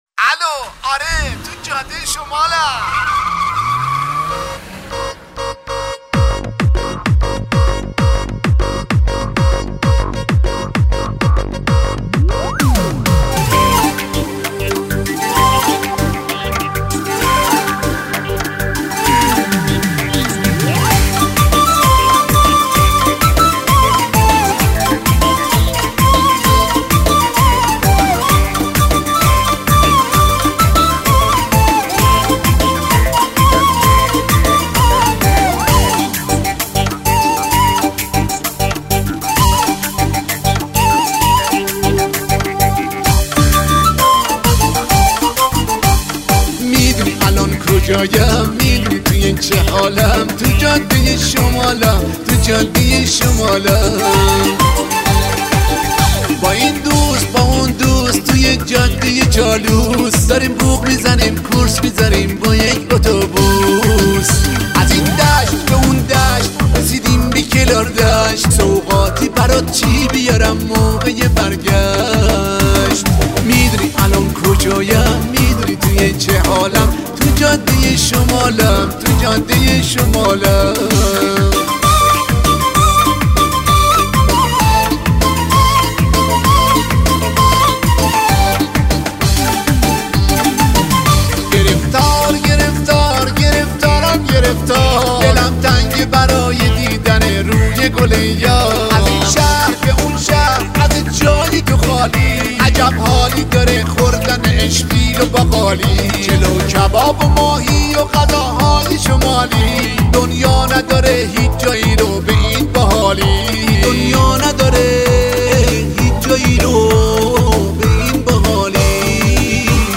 آهنگ قدیمی آهنگ شاد